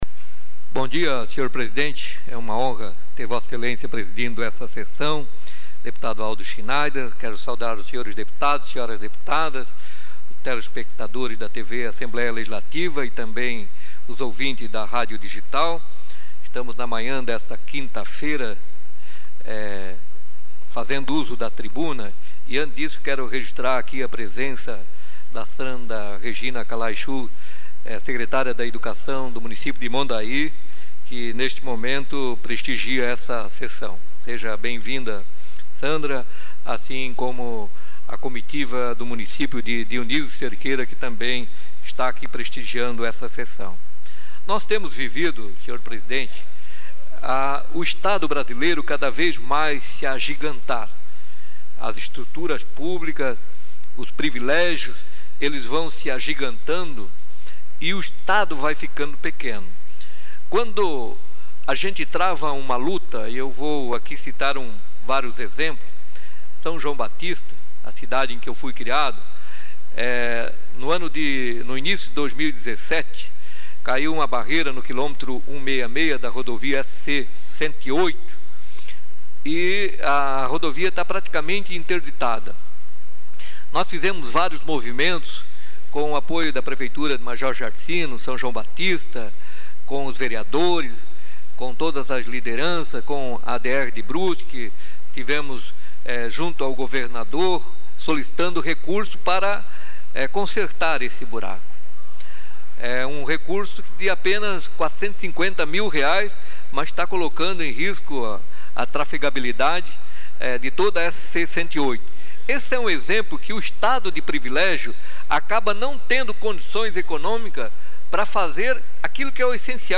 Confira aqui a manifestação dos deputados em tribuna durante a Sessão Ordinária desta quinta-feira (15):